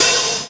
Index of /90_sSampleCDs/Classic_Chicago_House/Drum kits/kit01
cch_06_hat_open_high_hiss_lynn.wav